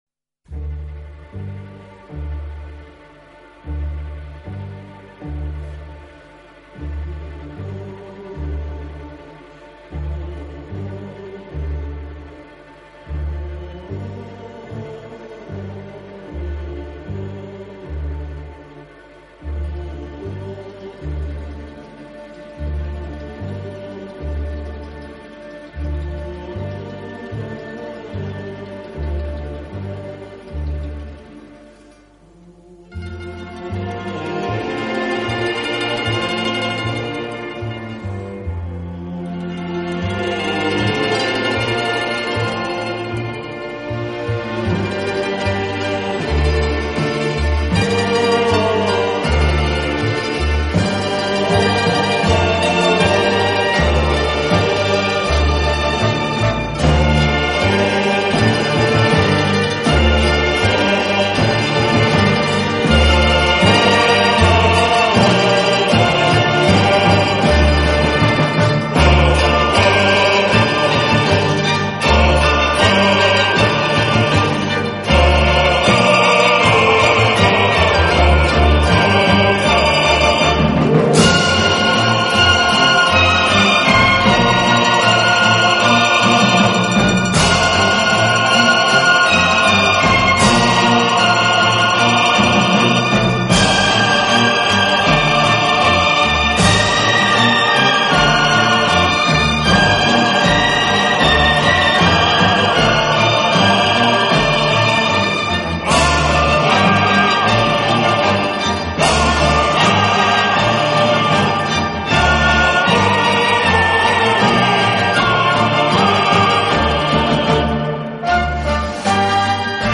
【顶级轻音乐】
他以清晰，明快的音乐风格，浪漫，华丽